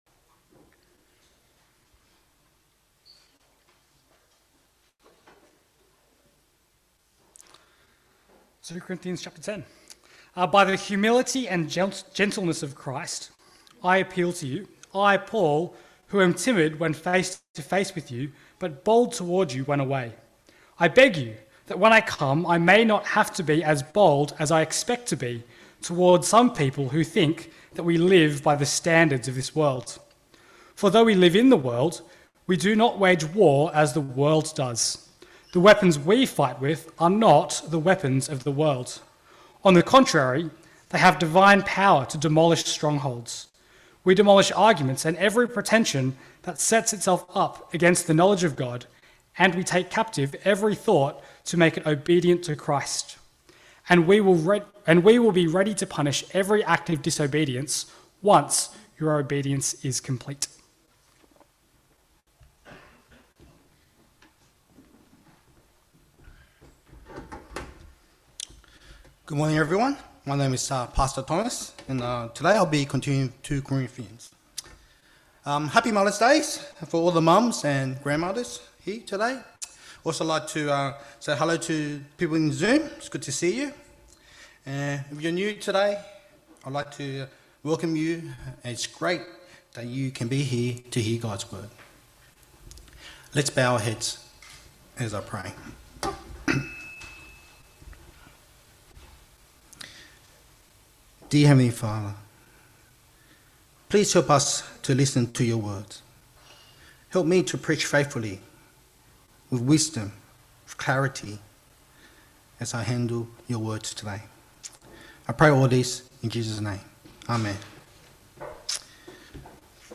Sermons English - The Chinese Christian Church